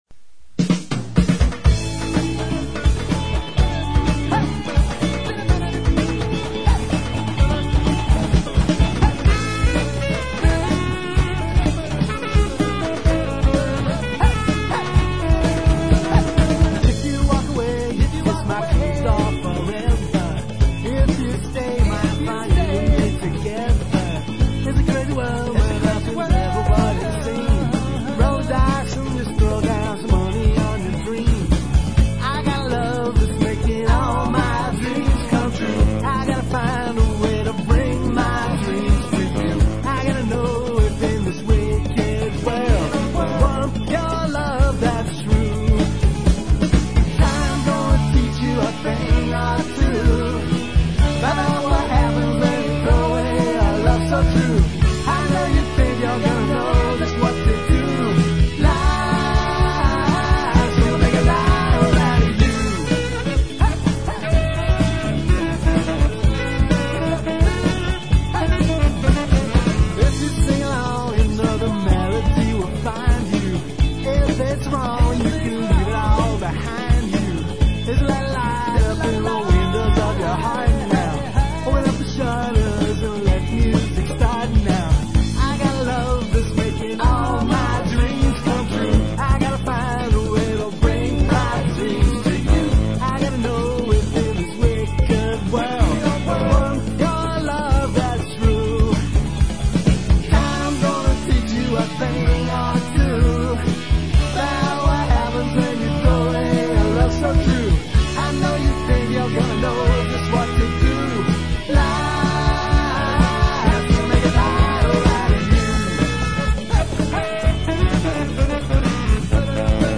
5-piece rock-n-roll outfit